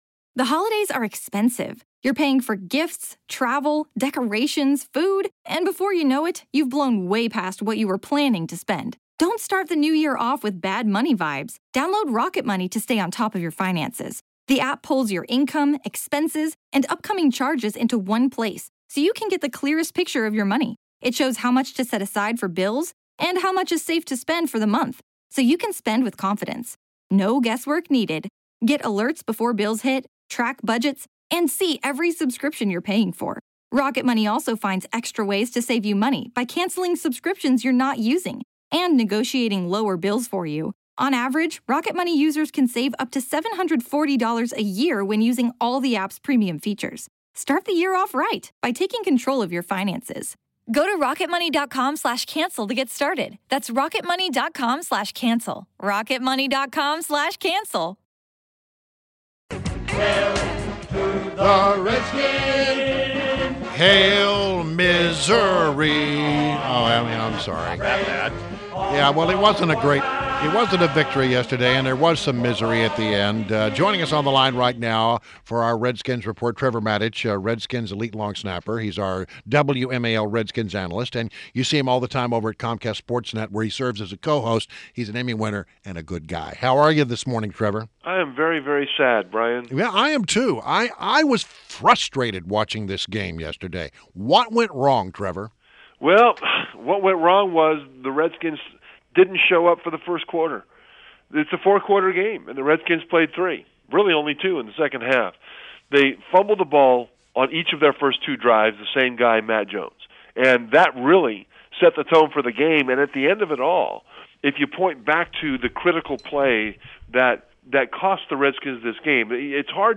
WMAL Interview - TREVOR MATICH - 10.24.16
INTERVIEW — TREVOR MATICH — Redskins elite long snapper, WMAL’s Redskins analyst and Comcast SportsNet co-host